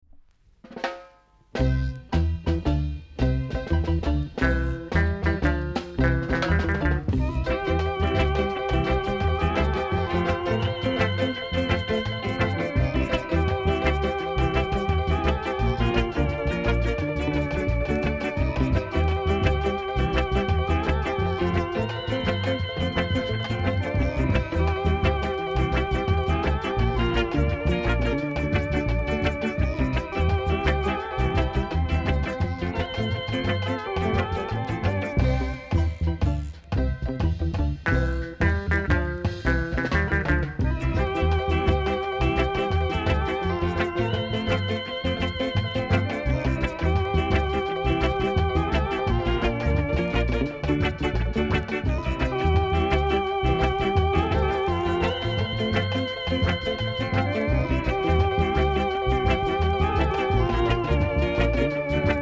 ※小さなチリノイズが少しあります。
SKINS COVER!!